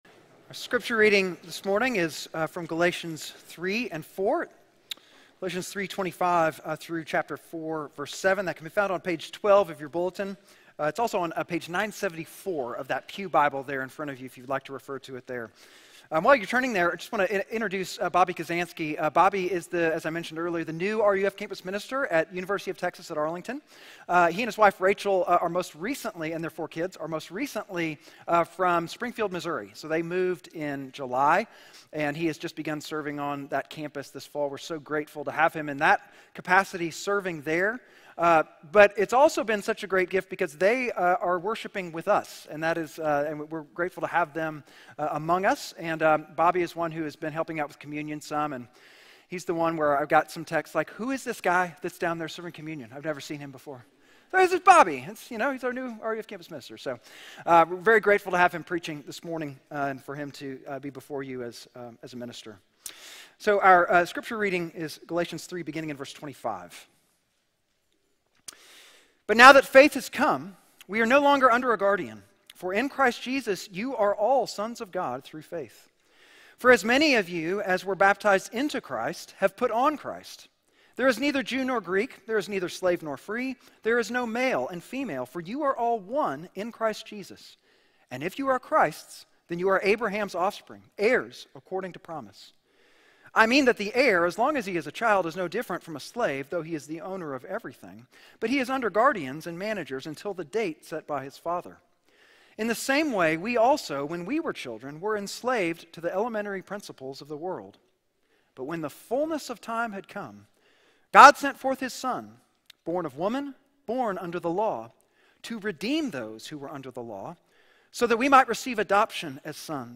Galatians 3:25-4:7 Service Type: Sunday Galatians 3:25-4:7